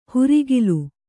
♪ hurigilu